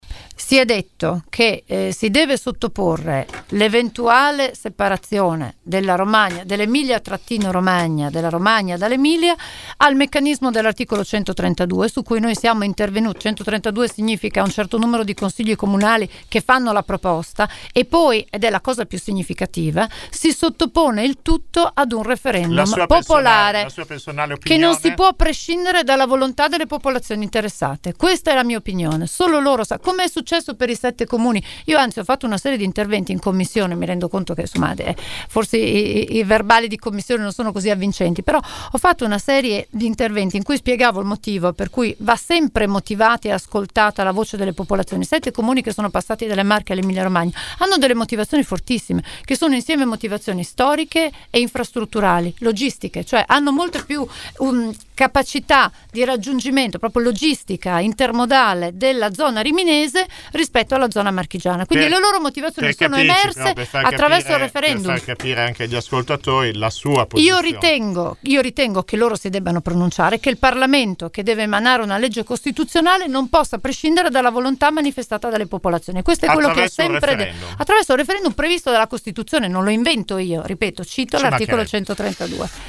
Ecco una sintesi dell’intervista andata in onda all’interno di Angolo B.